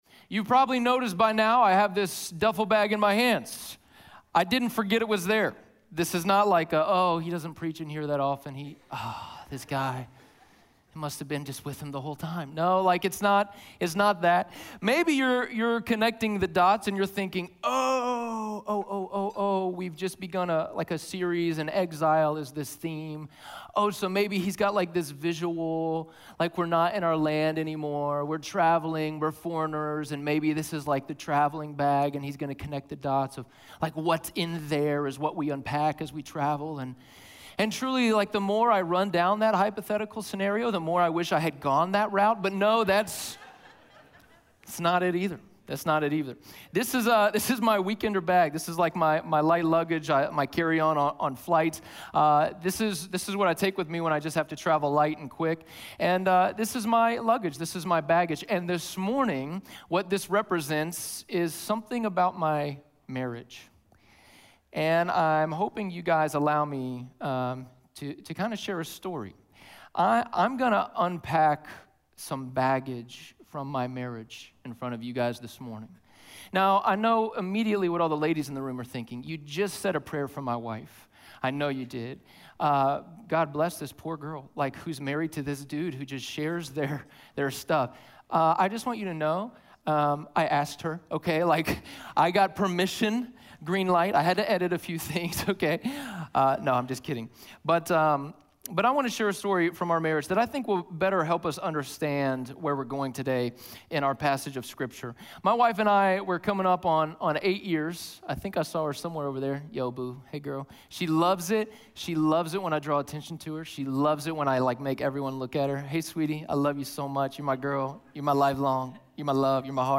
Daniel 1:8-21 Audio Sermon Notes (PDF) Ask a Question A number of years ago I found myself arguing with my wife about plates - wedding china to be exact.